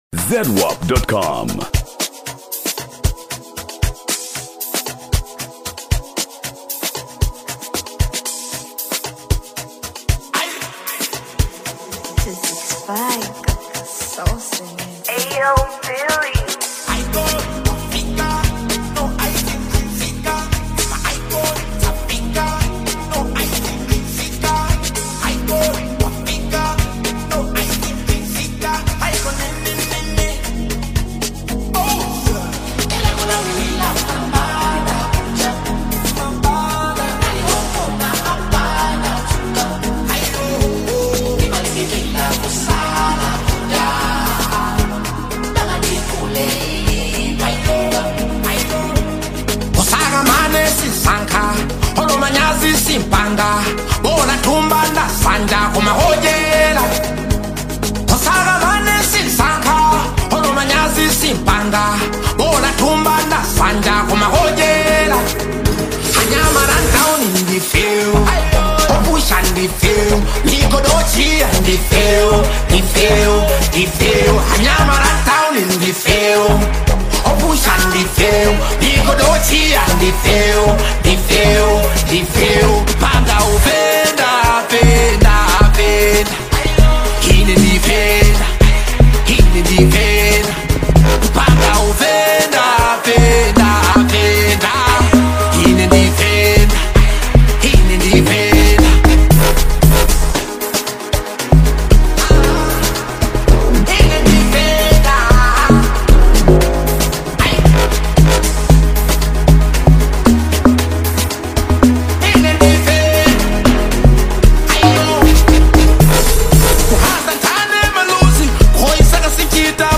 Latest Malawi Afro-Beats Single (2026)
Genre: Afro-Beats